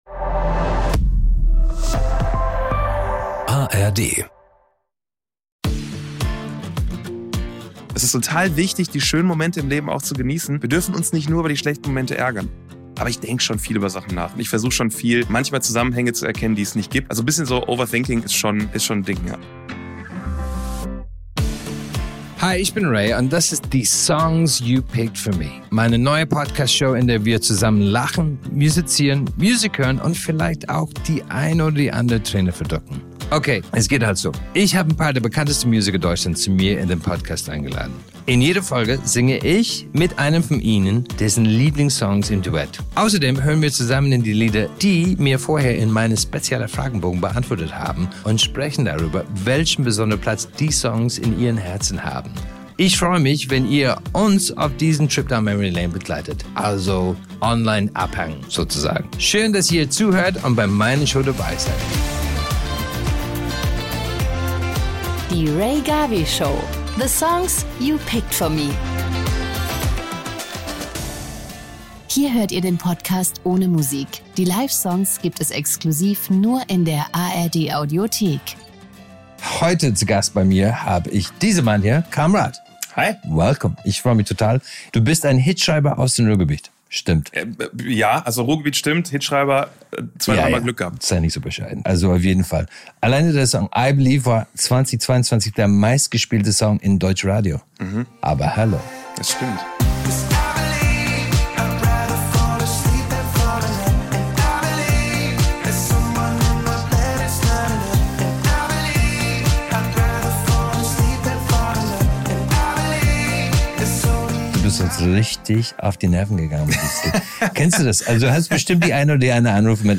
In dieser Folge begrüßt Rea Garvey den Sänger Tim Kamrad – bekannt durch seinen Ohrwurm-Hit „I Believe“. Gemeinsam sprechen sie darüber, wie sehr seine Familie ihn unterstützt hat, warum man als Musiker einen langen Atem braucht und manchmal sogar im Regen ohne Publikum spielen muss, um am Ende erfolgreich zu werden. Rea möchte wissen, woher Tim seine Energie nimmt, warum er sich mit einem Einser-Abitur dazu entschieden hat, Musiker zu werden und was ihn am Musikmachen begeistert. ußerdem geht es um Justin Timberlakes „Cry Me A River“ – seinen ultimativen Breakup-Song – und welche Art von Musik ihn vor Auftritten pusht.